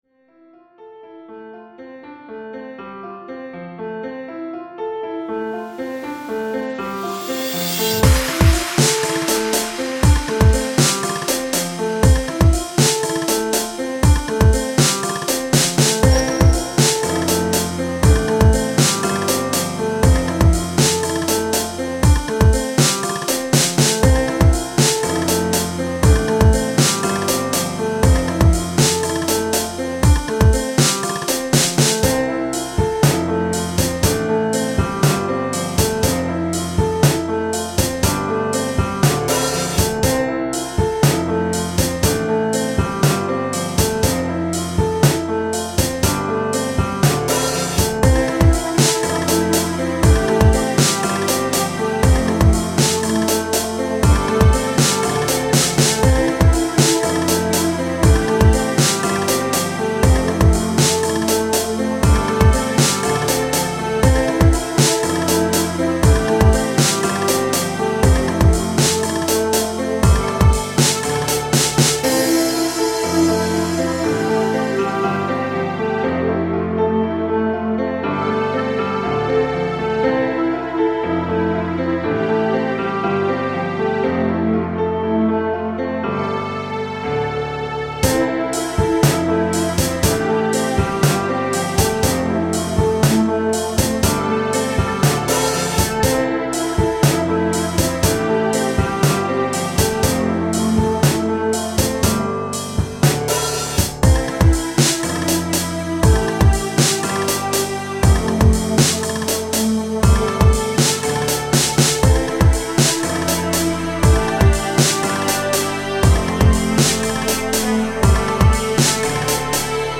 I added a celtic melody at the end to kinda give it that green, warm feeling